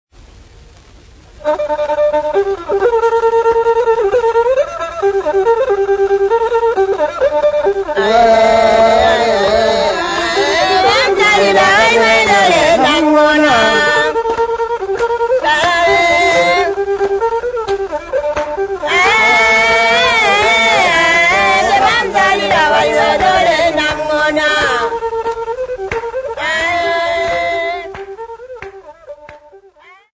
Four Old Ganda Women
Field recordings
sound recording-musical
Party song for parties with Ndingidi lute